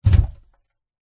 Звук засыпаемой земли, шум земли на гроб, внутреннее переживание